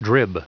Prononciation du mot drib en anglais (fichier audio)
Prononciation du mot : drib